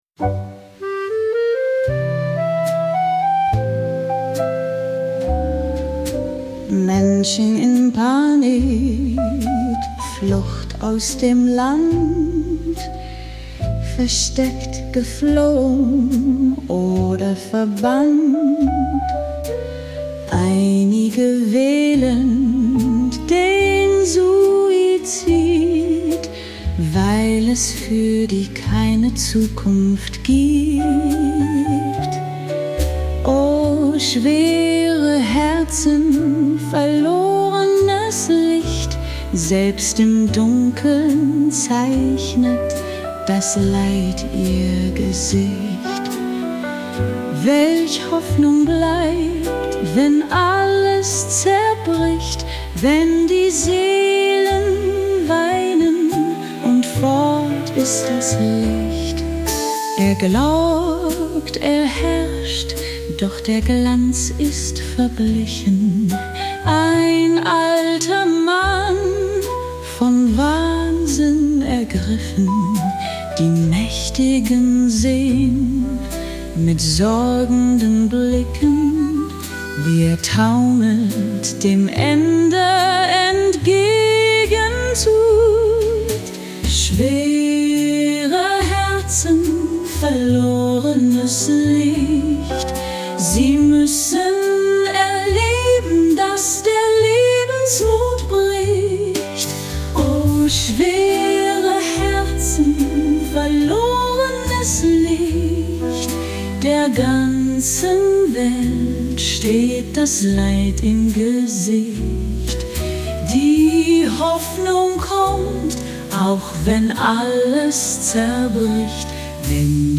Der 5. Akt der dramatischen Oper